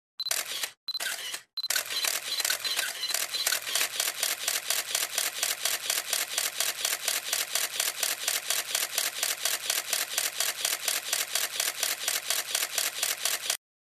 Звук при съемке сторис с эффектом Папарацци - Paparazzi